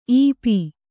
♪ īpi